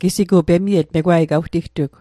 Pronunciation Guide: me·gwaayk